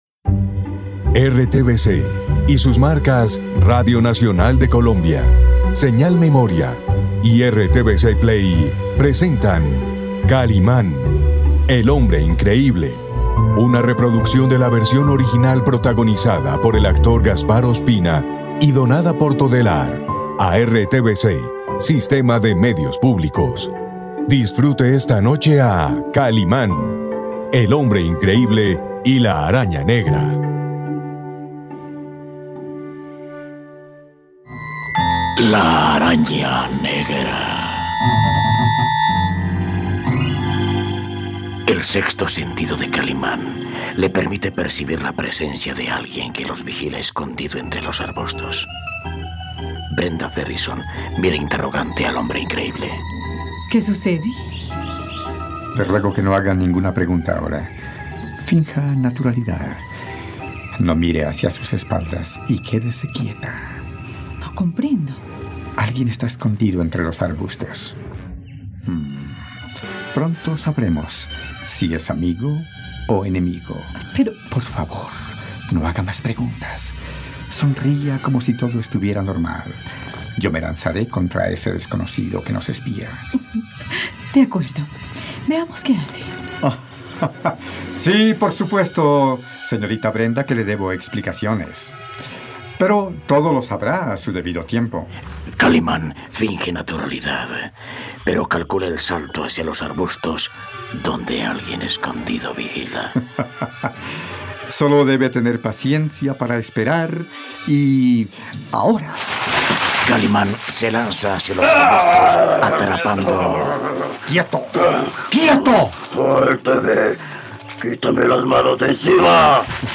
Podcast narrativo en español.
radionovela